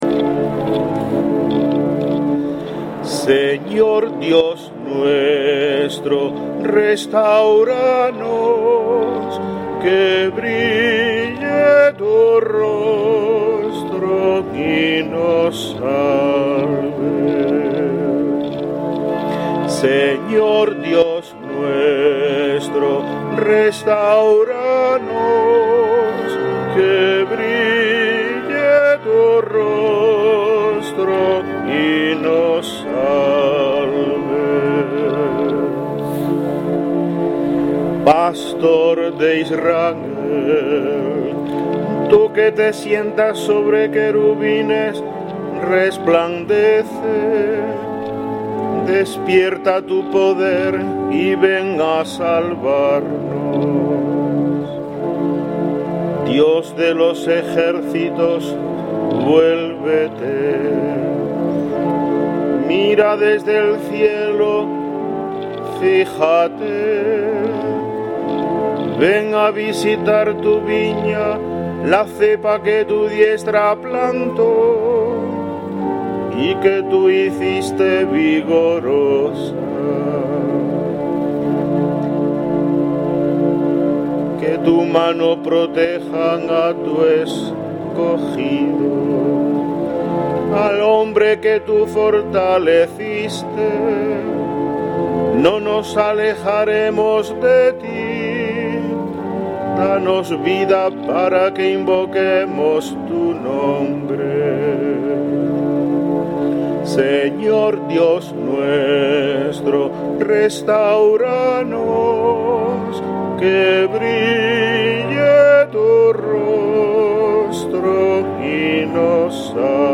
Salmo Responsorial 79/ 2-3;; 15-19